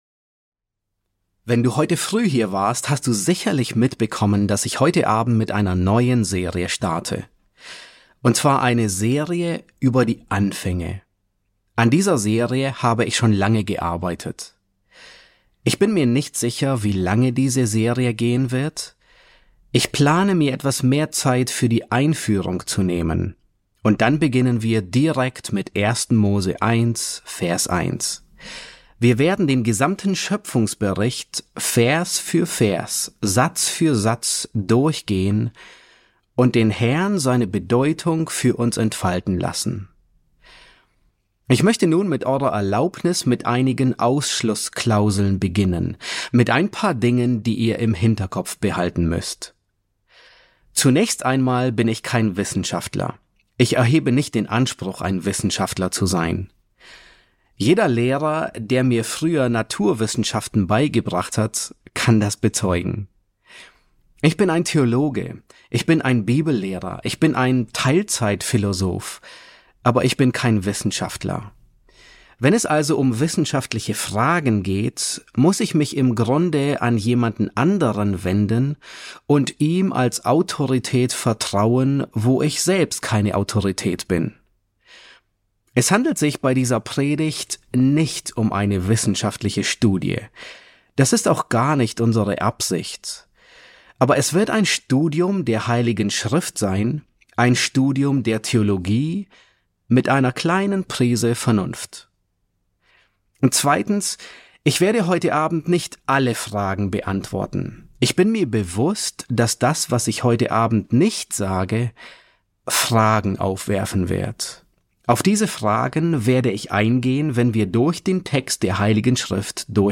S6 F1 | Die Schöpfung: Entweder du glaubst daran oder nicht, Teil 1 ~ John MacArthur Predigten auf Deutsch Podcast